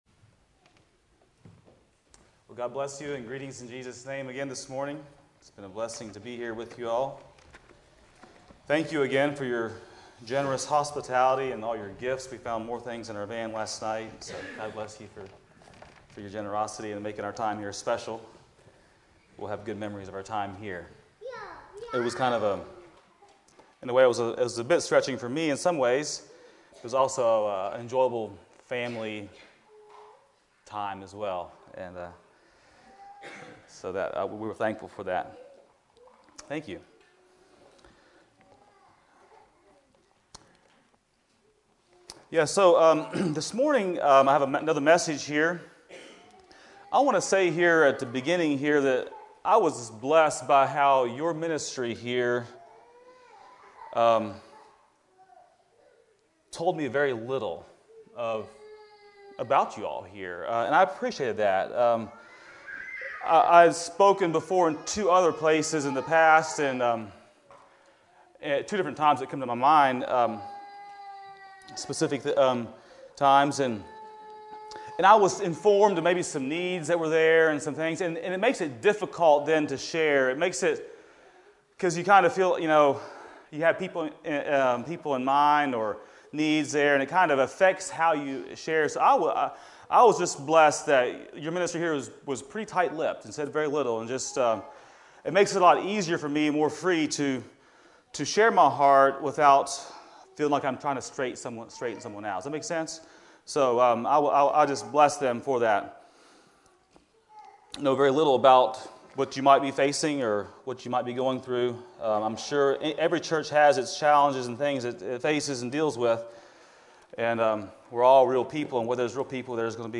2024 Revival Meetings
Sunday Morning Sermons